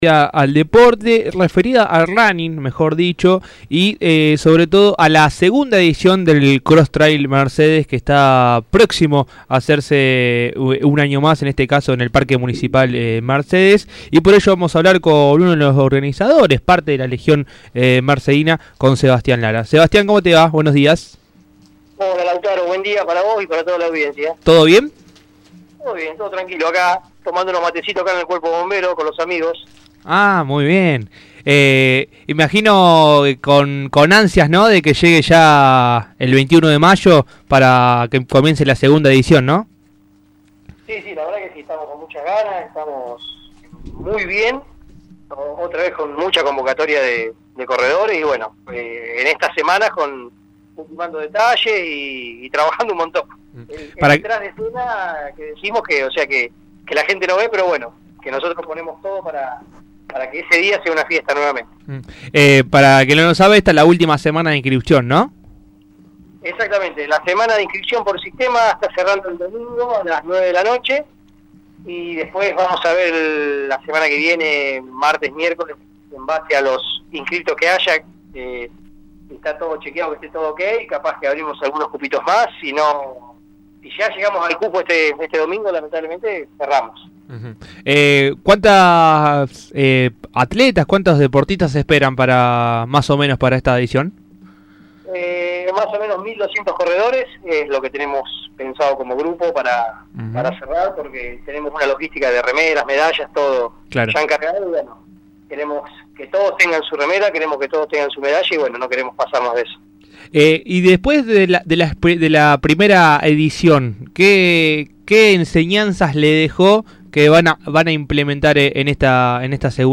charló durante la semana con Dato Posta Radio y contó más detalles de cómo vienen los preparativos para el evento.